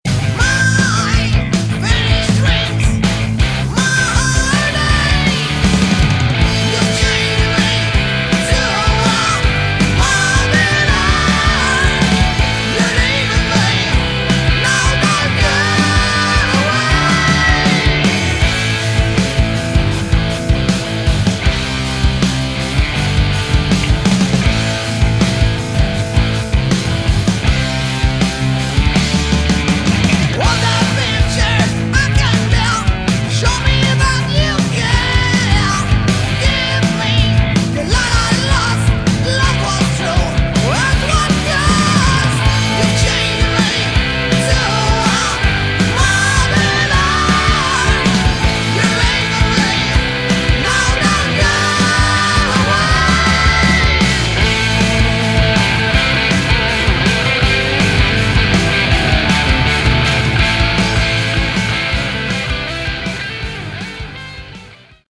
Category: Hard Rock
Vocals
Lead Guitars
Rhythm Guitar
Bass
Drums